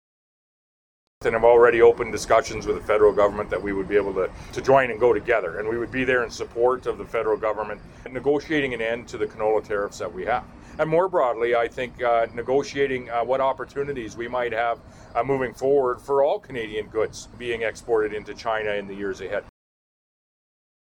Premier Moe spoke to reporters on Wednesday near a canola crushing plant in Yorkton.